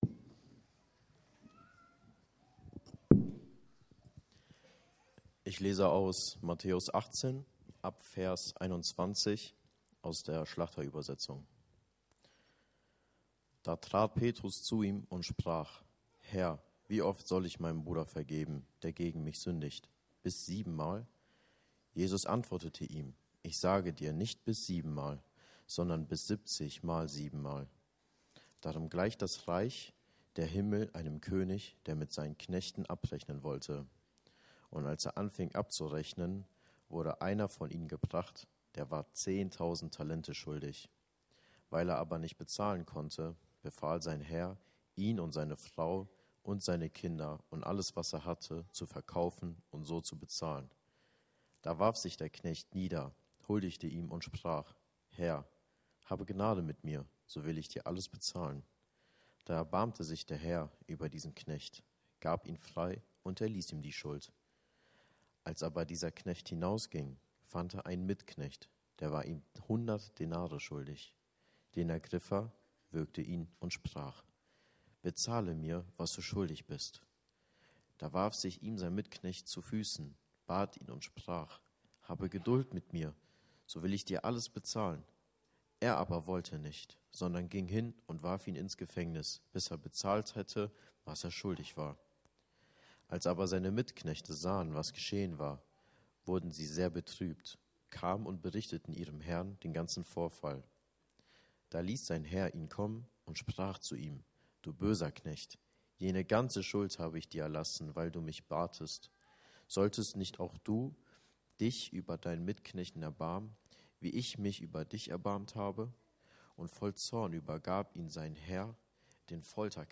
Predigten FCGL Vergebung Play Episode Pause Episode Mute/Unmute Episode Rewind 10 Seconds 1x Fast Forward 30 seconds 00:00 / 32:22 Datei herunterladen | Audiolänge: 32:22 | Aufgenommen am April 27, 2025